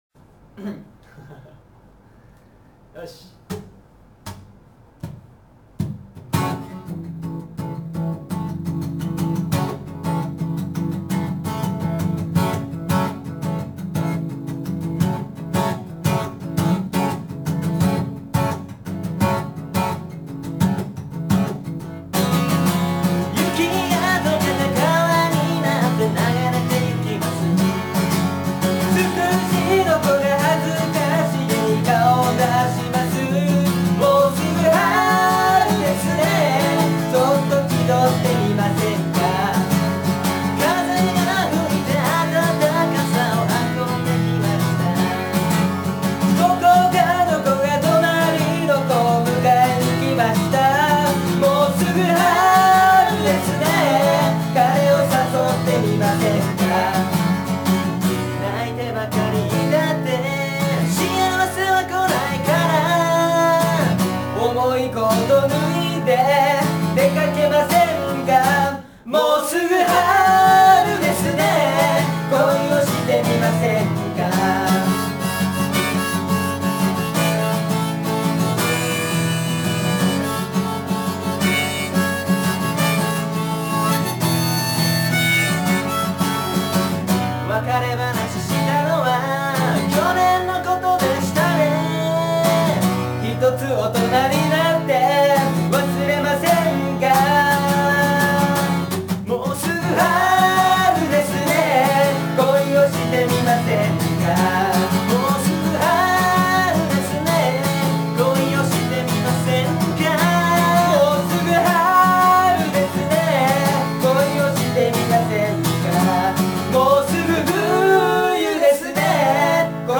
【練習段階】
聞き苦しい点が多々ありますが、練習段階を一発録りしたものなので勘弁してくださいm(_ _)m
ハープのフレーズを多少アレンジしております。